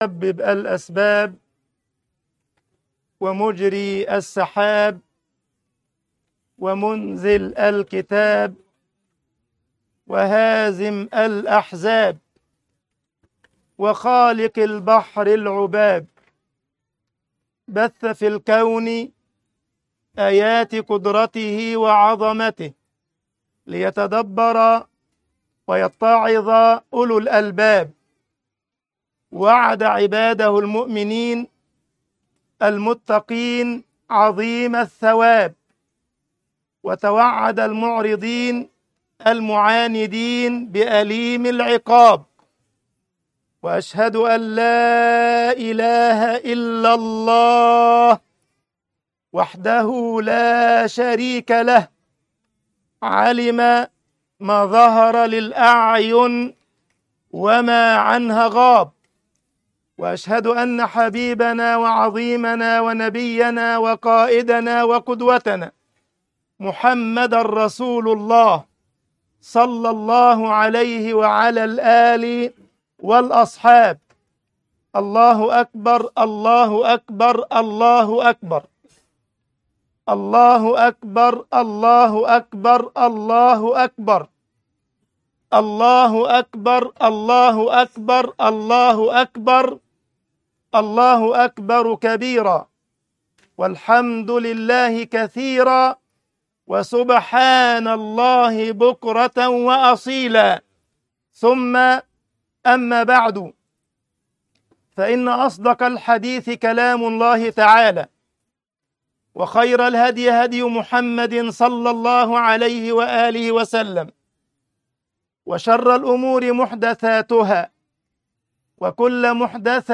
خطبة عيد الفطر الاسْتِغْفَارُ مِفْتَاحُ الْخَيْرِ